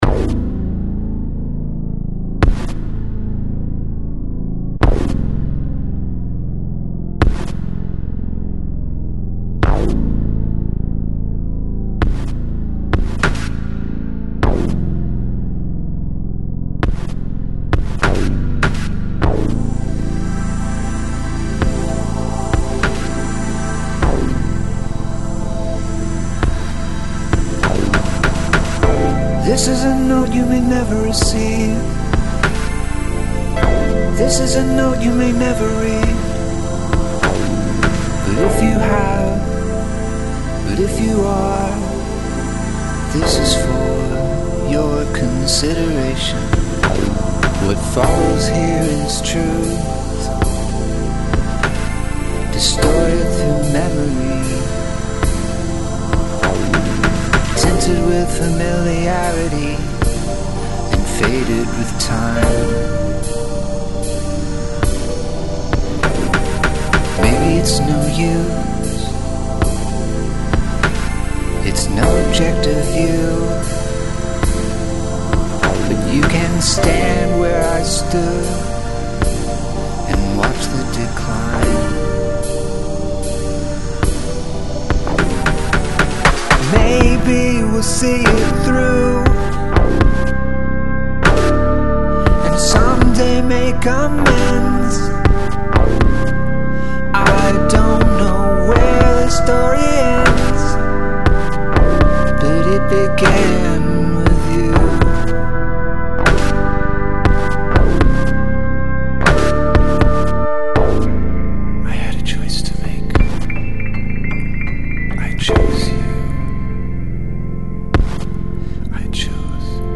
Used both acoustic piano and e-piano